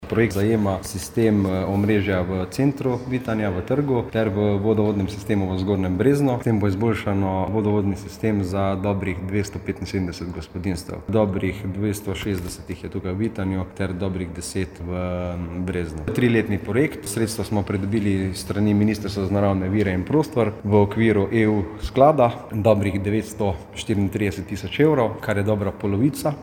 Da gre za zgodovinski trenutek, je ocenil župan Andraž Pogorevc, ki je o naložbi za Radio Rogla med drugim povedal: